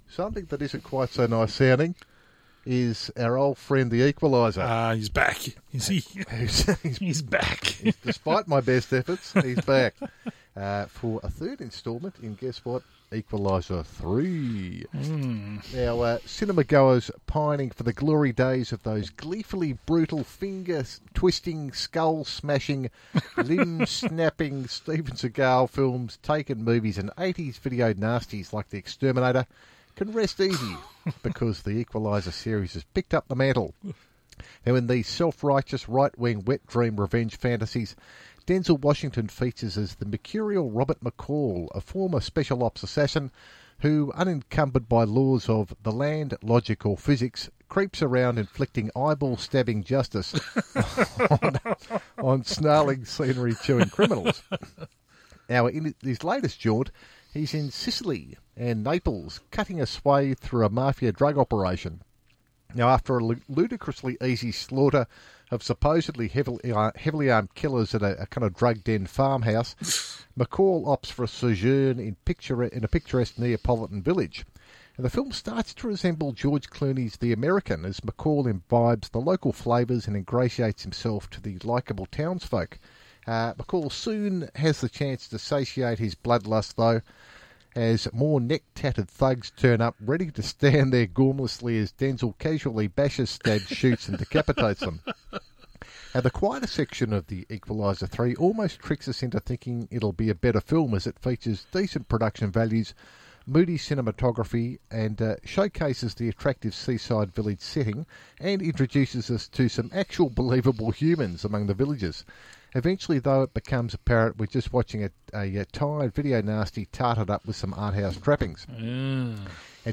Here’s my review of the The Equalizer 3 as presented on ‘Built For Speed’ on Fri 8th Sept 2023.
Film-review-The-Equaliser_3.wav